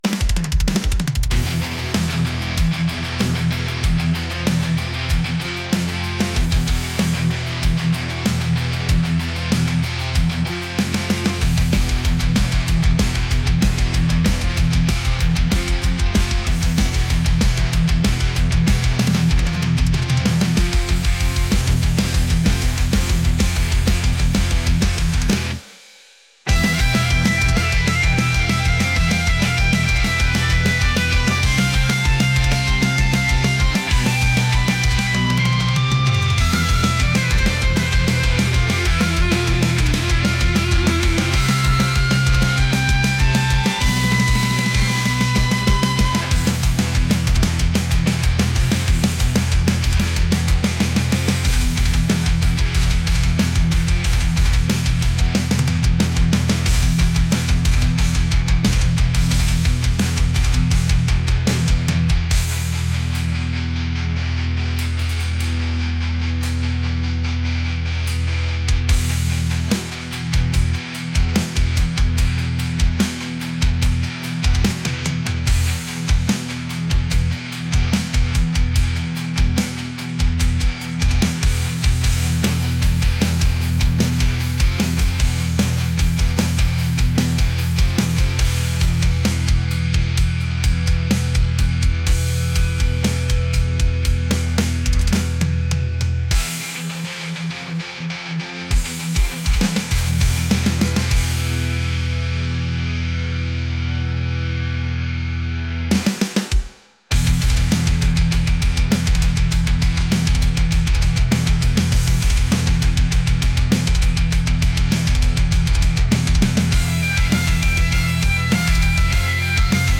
aggressive | metal | intense